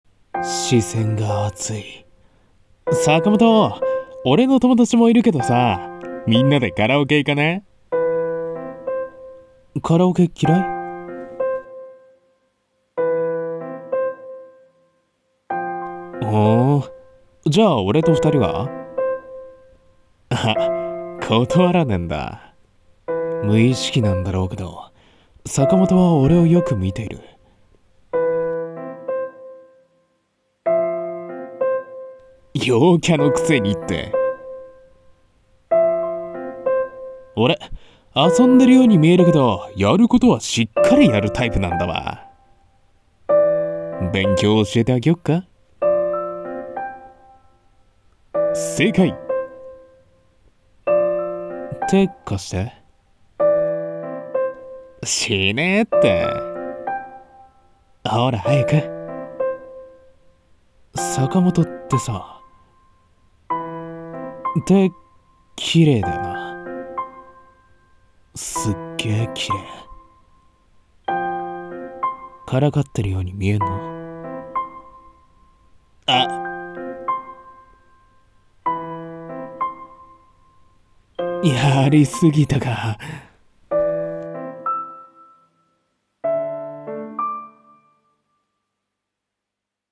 【BL声劇】